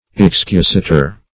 excusator - definition of excusator - synonyms, pronunciation, spelling from Free Dictionary
Search Result for " excusator" : The Collaborative International Dictionary of English v.0.48: Excusator \Ex`cu*sa"tor\, n. [L.] One who makes, or is authorized to make, an excuse; an apologist.